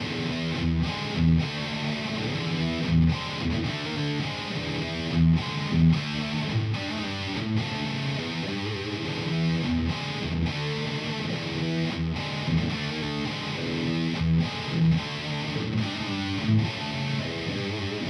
Each side has slightly different mic placement. I was using my 81-82 JCM 800.